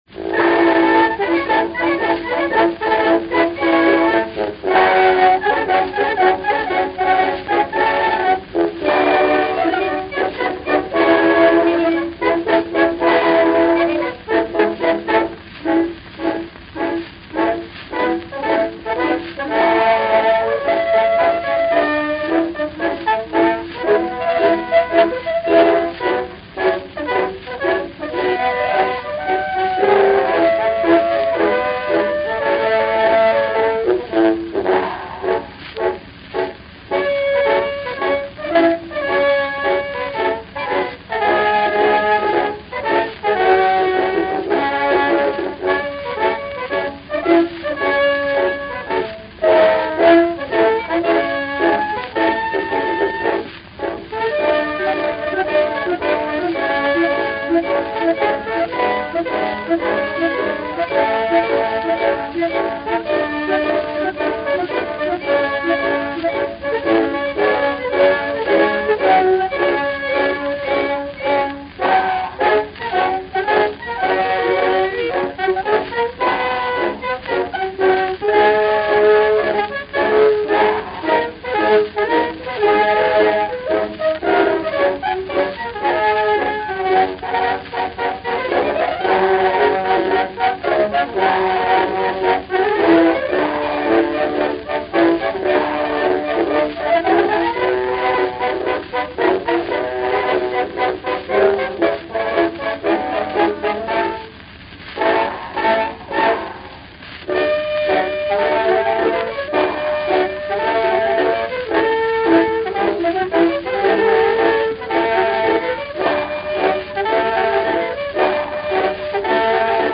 starinnyiy-frantsuzskiy-marsh.mp3